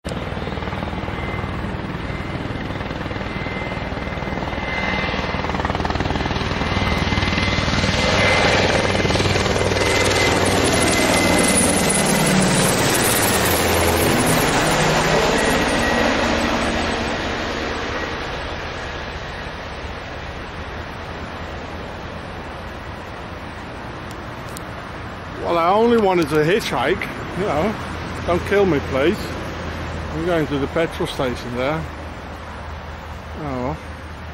Army Helicopter Low Pass By while Hitchhiking; Pharaoh's Worldwide Military